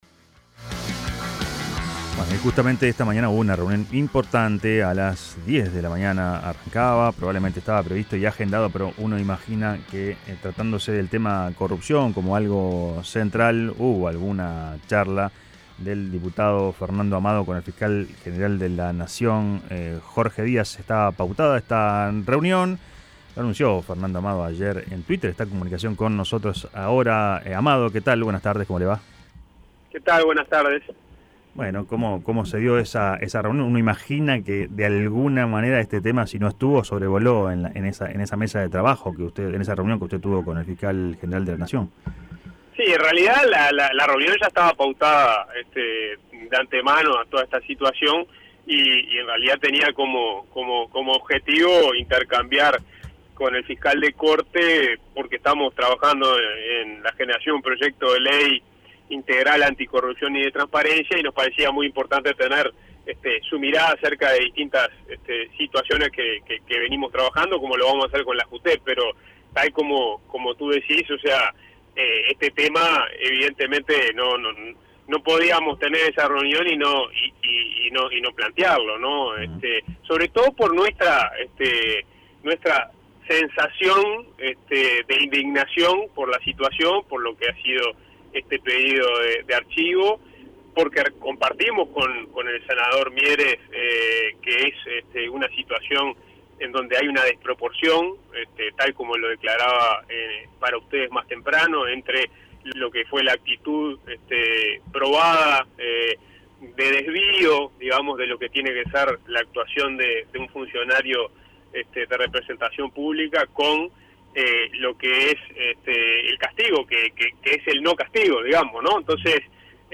El parlamentario dijo a Fuga de Noticias que es fundamental que los funcionarios estén obligados a justificar detalladamente su enriquecimiento al terminar el periodo de gobierno.